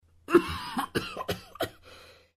cough1.mp3